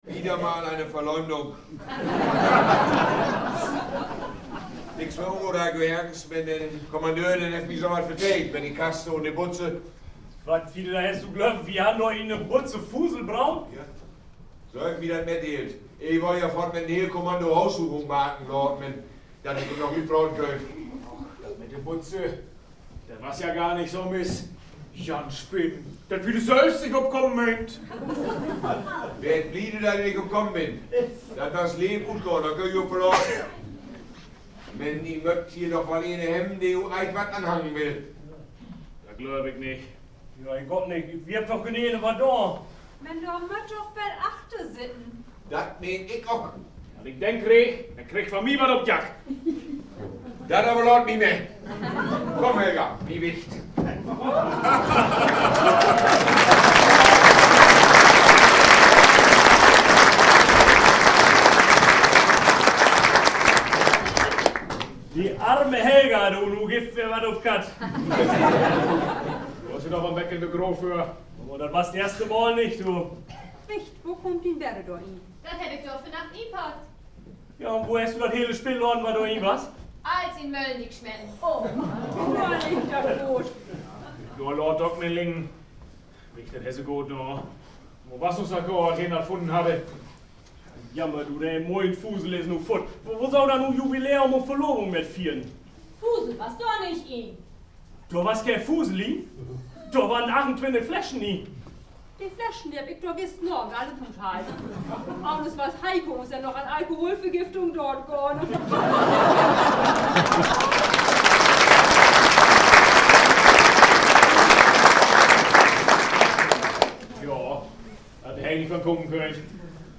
Dorfabend in Uelsen
Aufführungsort war auch diesmal wieder die Aula (Feierraum) der Schule Uelsen.